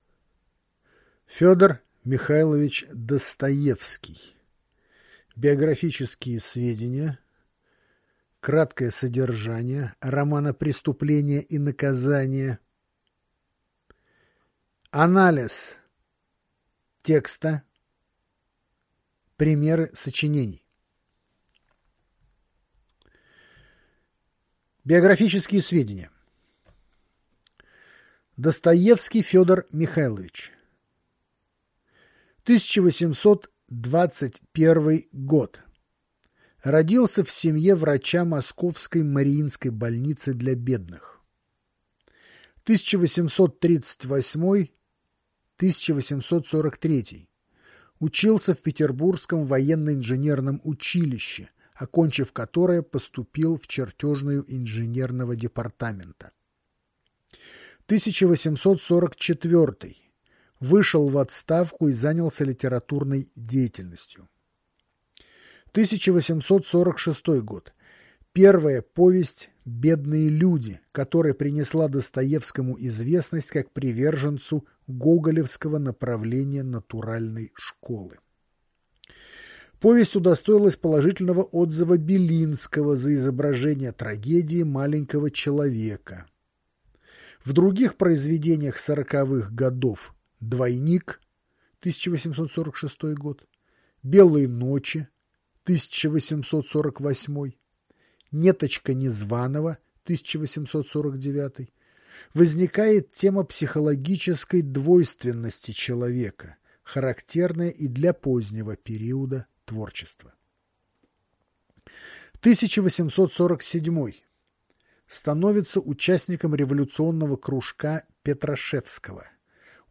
Аудиокнига Брошюра Ф. М. Достоевский «Преступление и наказание».